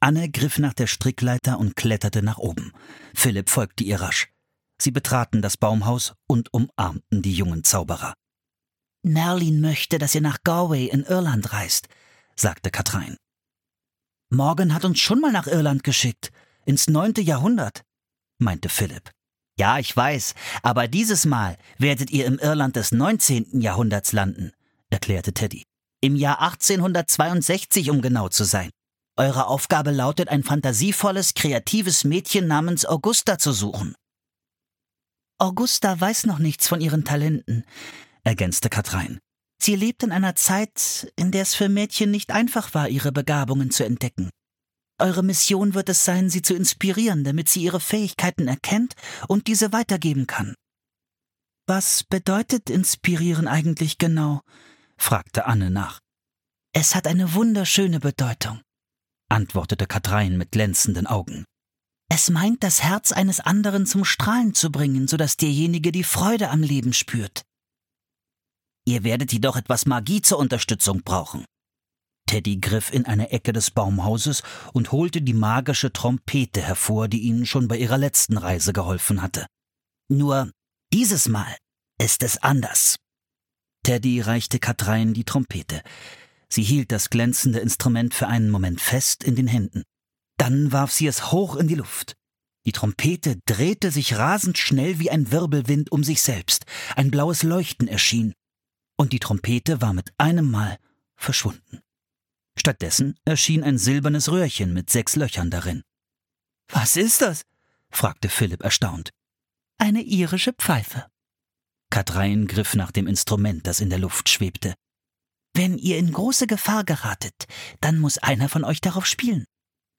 Gefangen im Elfenwald (Das magische Baumhaus 41) - Mary Pope Osborne - Hörbuch